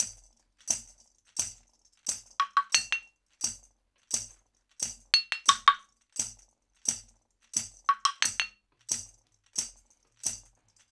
88-PERC6.wav